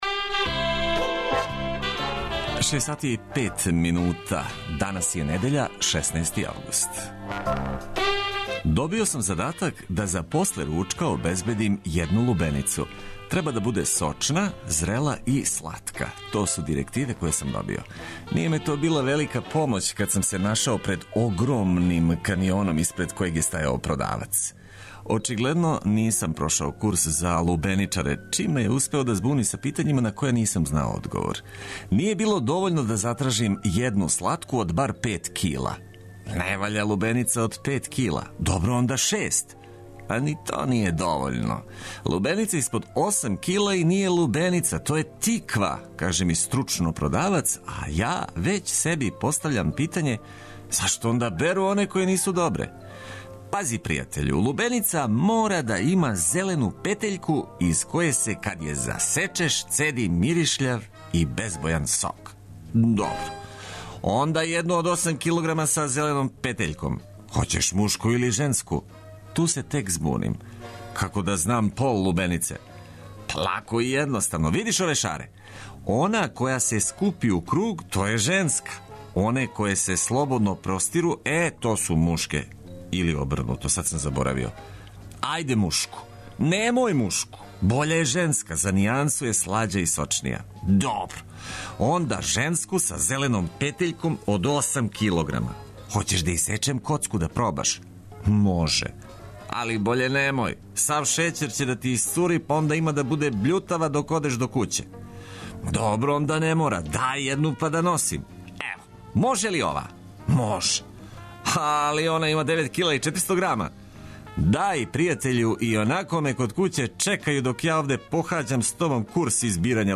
Корисне информације уз обиље добре музике, то је одлика заједничког започињања новог дана, јединог који зовемо нерадним.